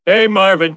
synthetic-wakewords
ovos-tts-plugin-deepponies_Homer Simpson_en.wav